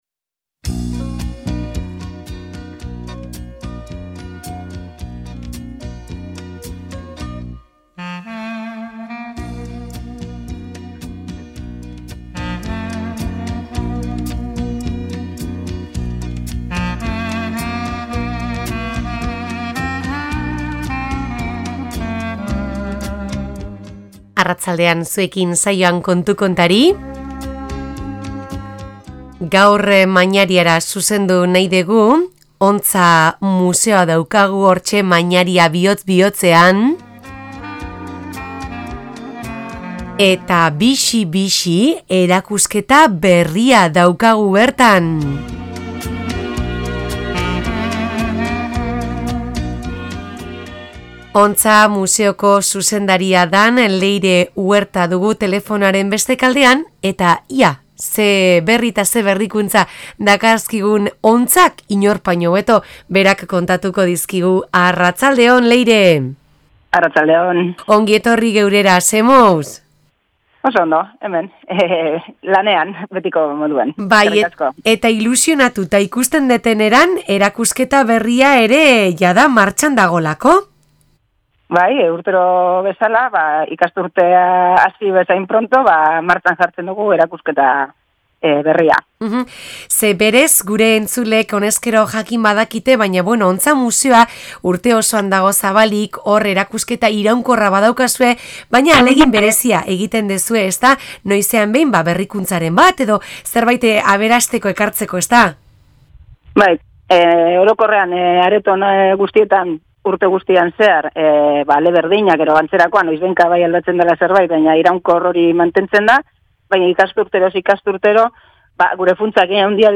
Alkarrizketa